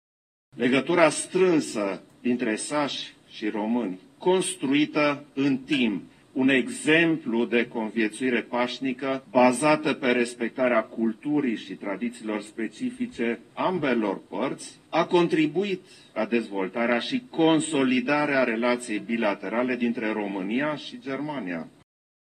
Preşedintele Klaus Iohannis a participat, în localitatea braşoveană Criţ, la evenimentele prilejuite de festivalul dedicat tradițiilor și culturii săsești din Țara Ovăzului, respectiv zona cuprinsă între Rupea și Sighișoara.
În plus, Klaus Iohannis a ținut să sublinieze faptul că relațiile foarte bune dintre românii și sașii din Transilvania, bazate pe respect reciproc, au contribuit inclusiv la buna colaborare dintre România şi Germania: